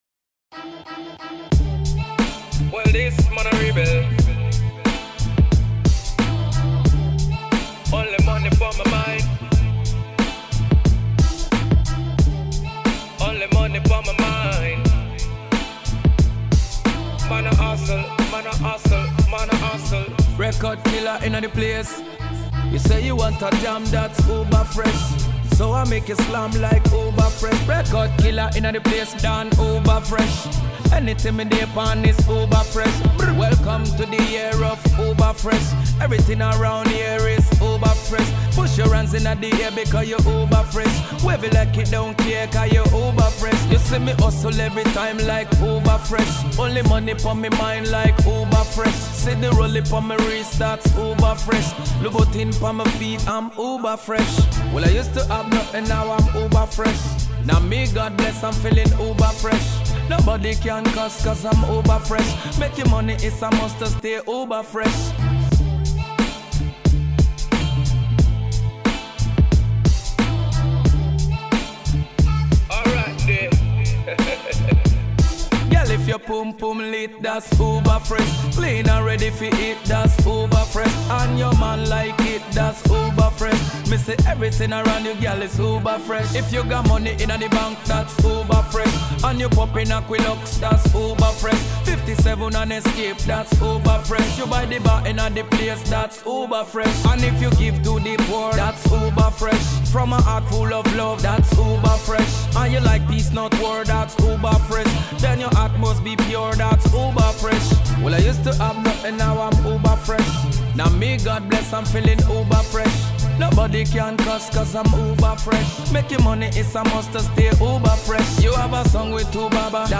Alternative Pop
an infectious contemporary pop cut with reggae influences.